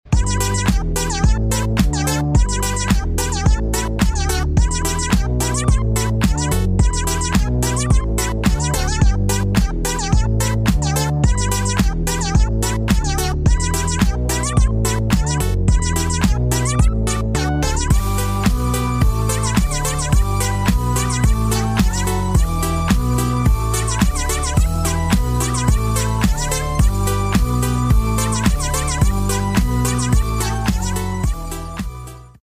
Baekhyun Interview: The Puppy 🐶Released